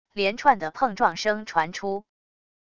连串的碰撞声传出wav音频